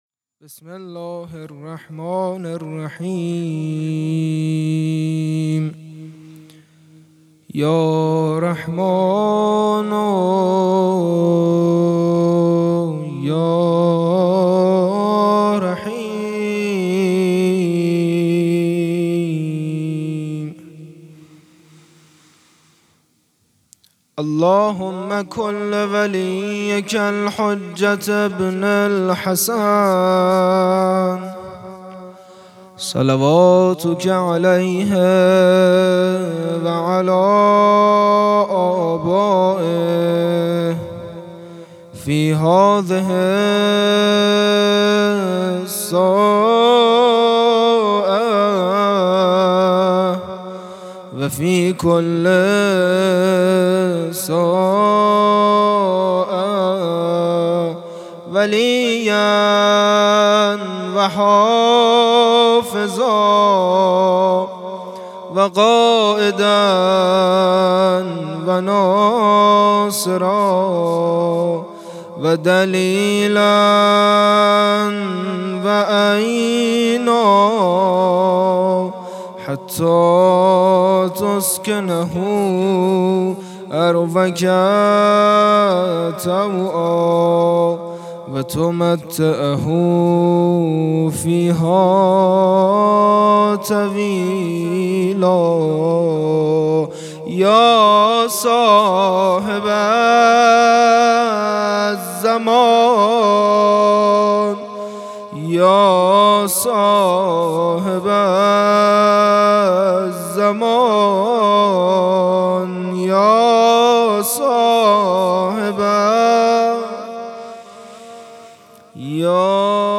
شب پنجم دهه سوم محرم الحرام ۱۴۴۵